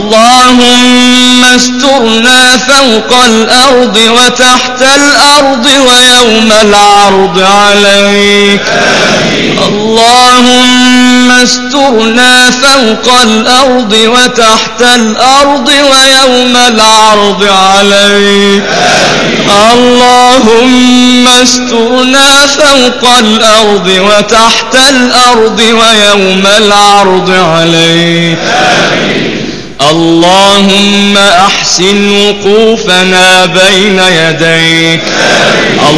.:| محمد جبــريل ،، دعاء موزون يخليك تهز غصب ذذذ |:.